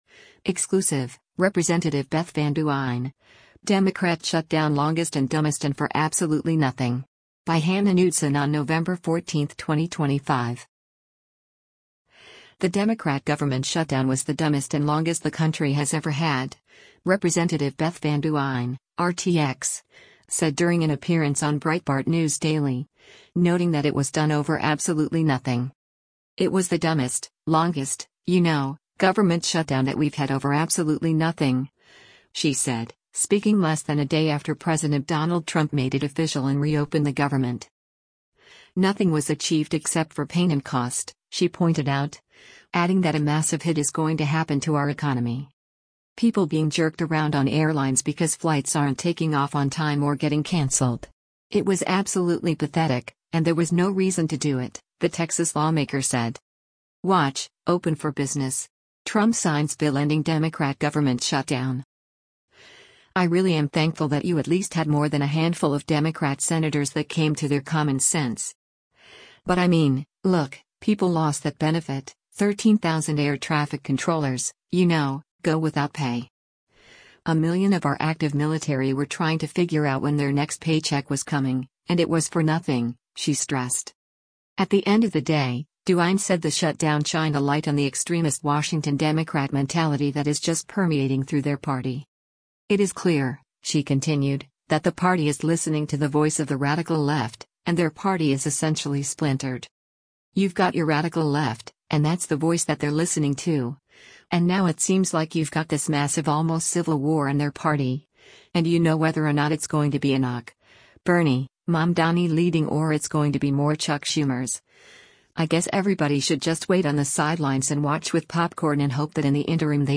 The Democrat government shutdown was the “dumbest” and “longest” the country has ever had, Rep. Beth Van Duyne (R-TX) said during an appearance on Breitbart News Daily, noting that it was done over “absolutely nothing.”